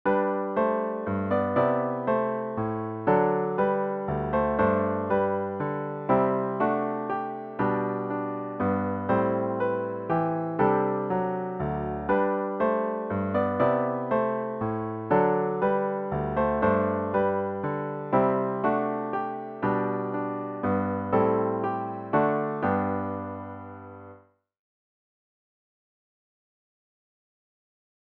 zz-447-We-Are-Forgiven-piano-only.mp3